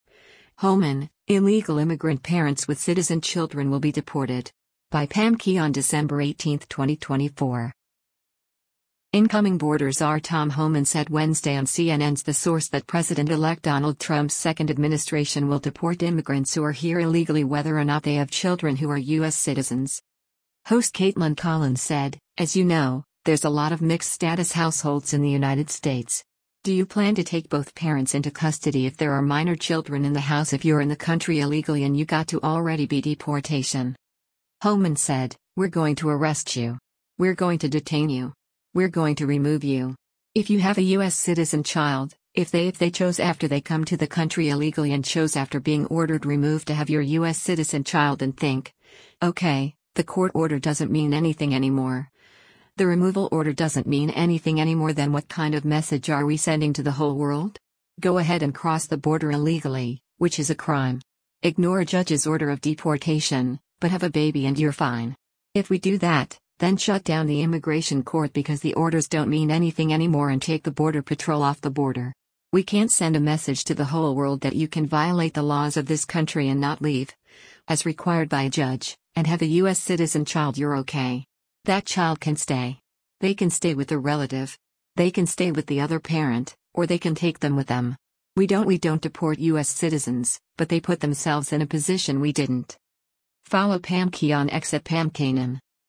Incoming Border Czar Tom Homan said Wednesday on CNN’s “The Source” that President-elect Donald Trump’s second administration will deport immigrants who are here illegally whether or not they have children who are U.S. citizens.